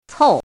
chinese-voice - 汉字语音库
cou4.mp3